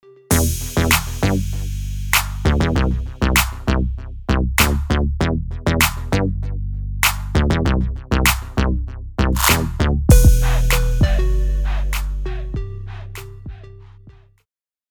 Знаю что это муг басс, но не могу сделать его таким же жирным как тут